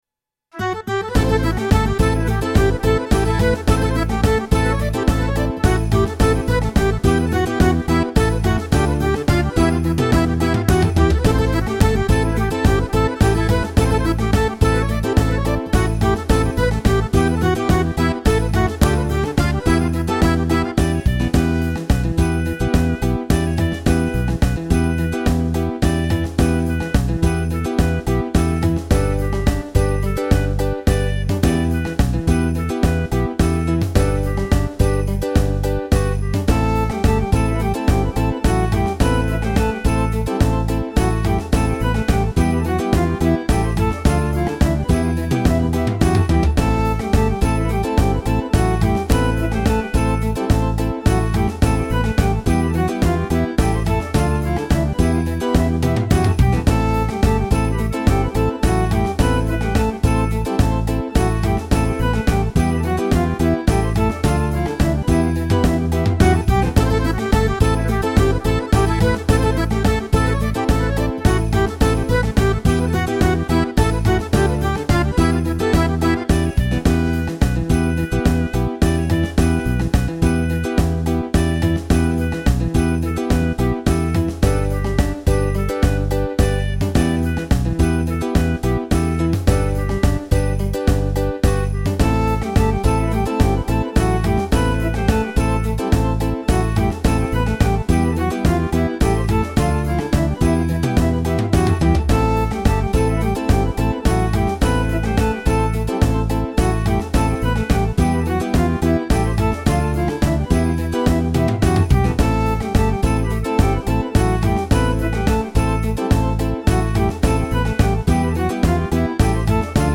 Podiaka